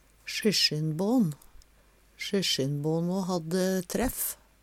syssjinbån - Numedalsmål (en-US)